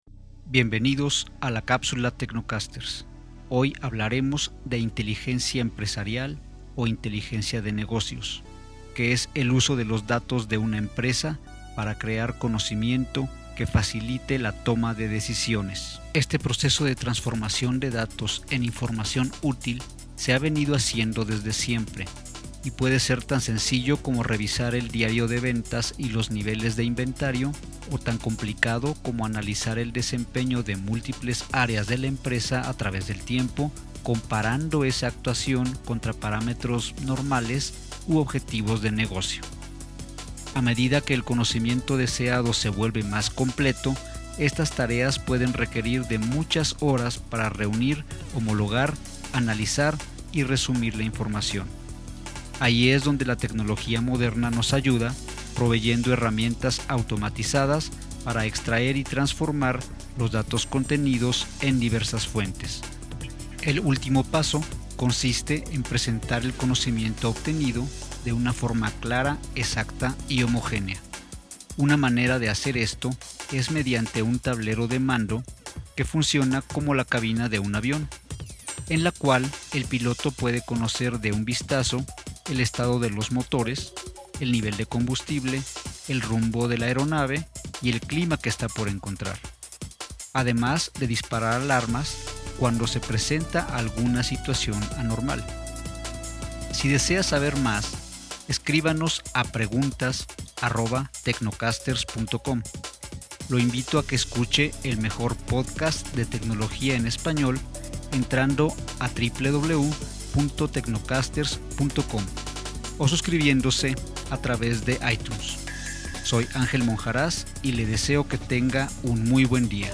Capsula para transmision en Radio.